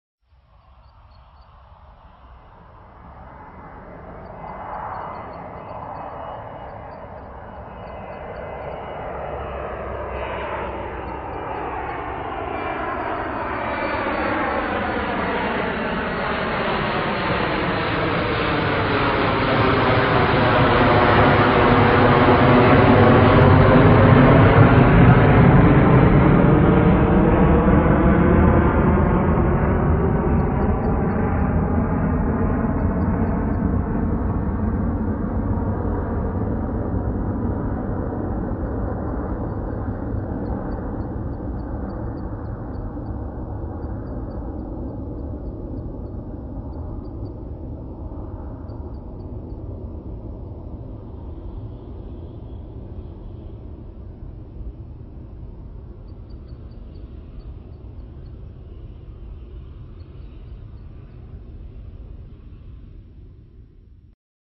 Flugzeug - Stimme -
holophonisch_flugzeug.mp3